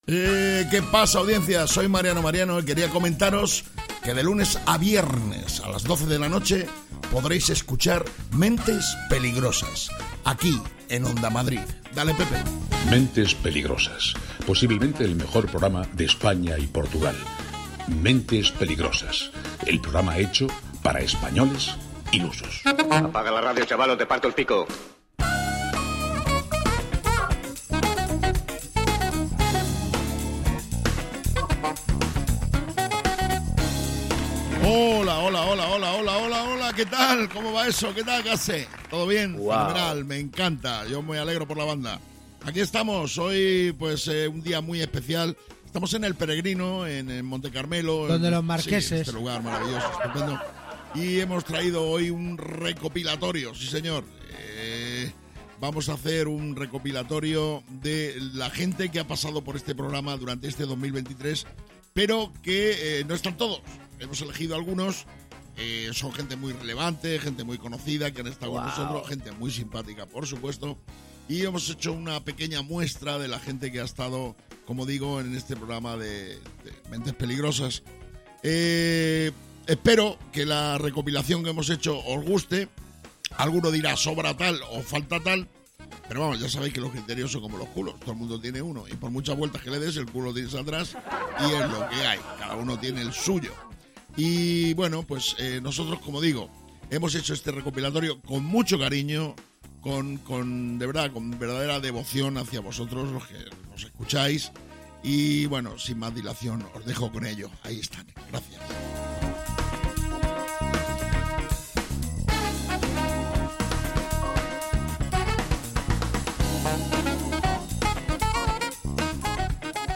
Mentes Peligrosas es humor, y quizás os preguntaréis, ¿y de qué tipo de humor es?